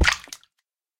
Minecraft Version Minecraft Version snapshot Latest Release | Latest Snapshot snapshot / assets / minecraft / sounds / mob / guardian / land_hit2.ogg Compare With Compare With Latest Release | Latest Snapshot
land_hit2.ogg